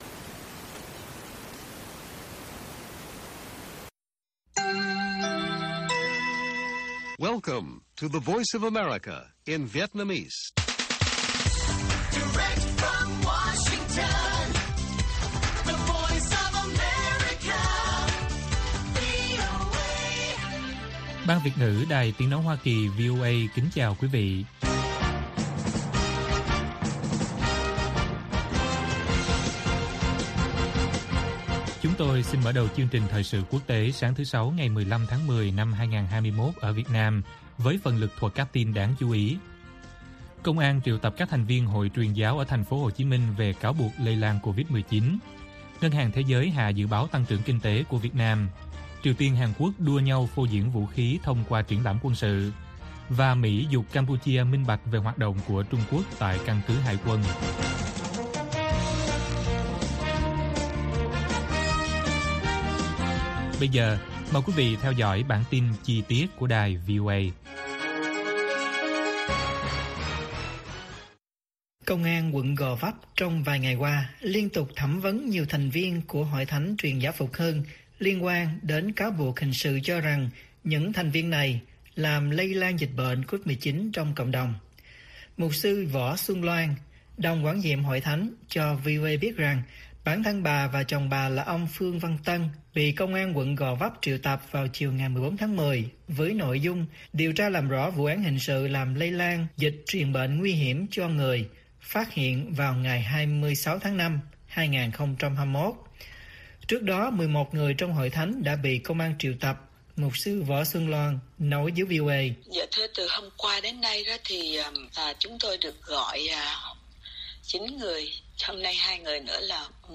Bản tin VOA ngày 15/10/2021